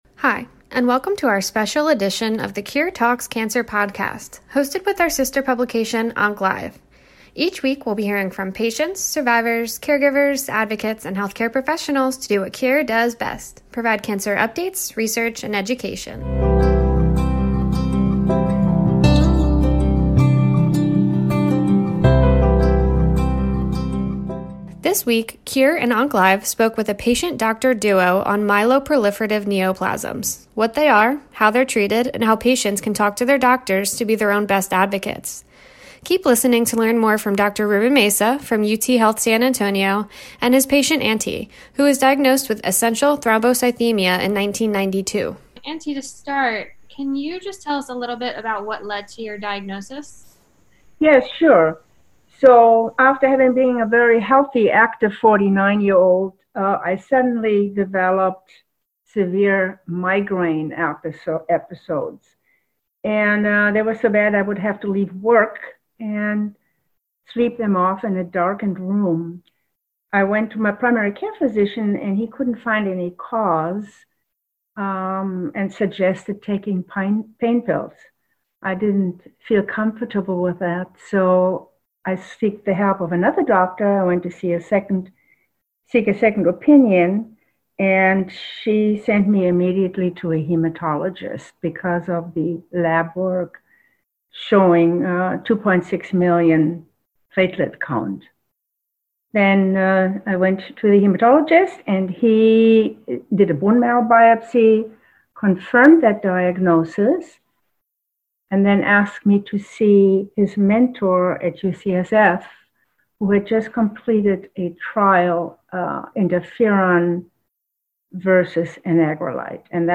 In this special edition of the “CURE Talks Cancer” podcast, we teamed up with our sister publication “OncLive on Air” to speak with a patient-doctor duo on myeloproliferative neoplasms.